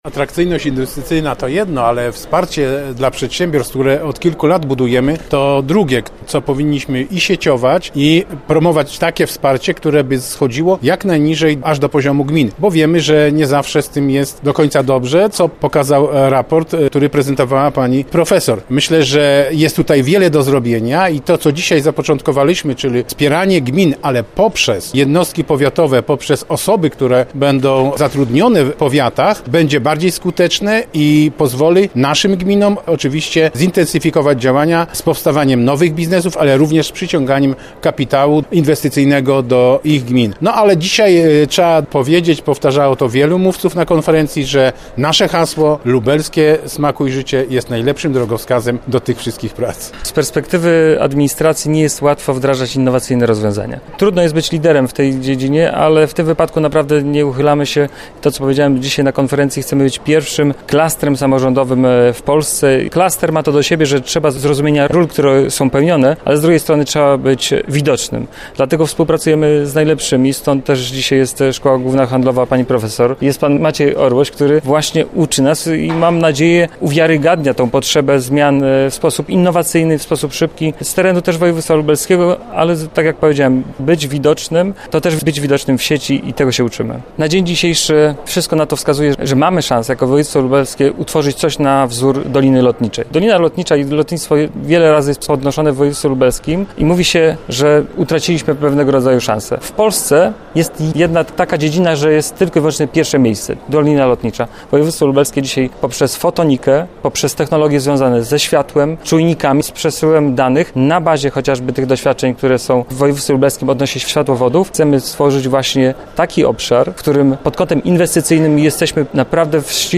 O tym, jak „złapać w sieć” potencjalnego inwestora i dlaczego miasto, gmina czy powiat powinny być dla inwestorów atrakcyjne rozmawiali w środę  (07.03) w Lublinie samorządowcy z całego naszego województwa.